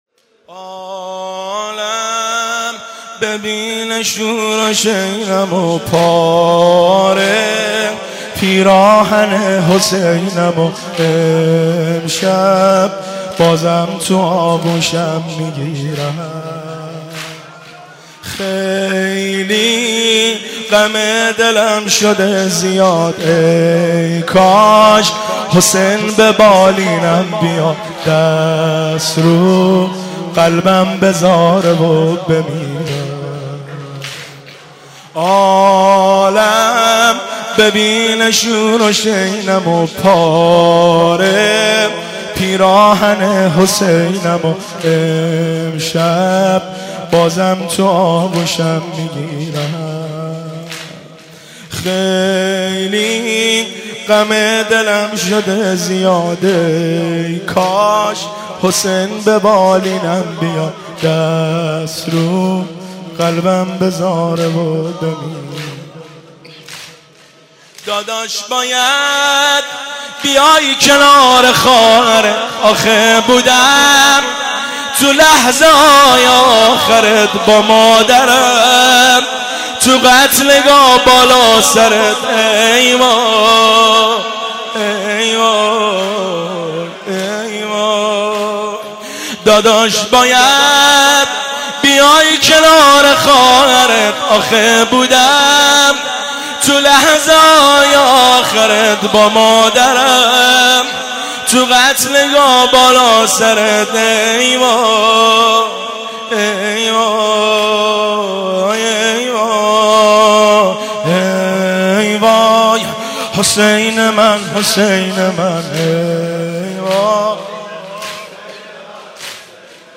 ( زمینه )